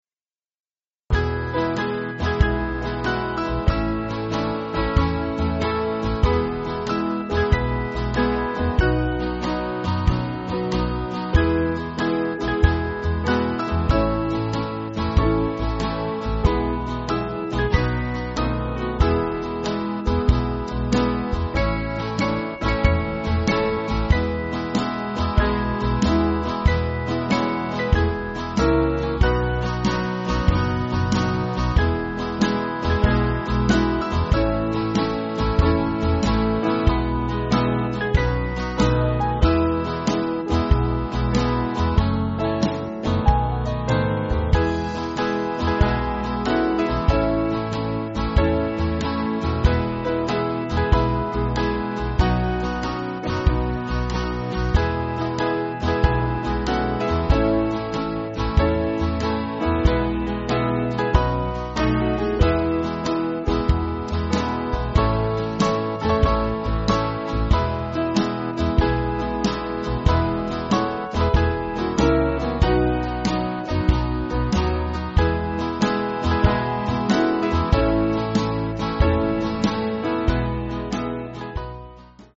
Country/Gospel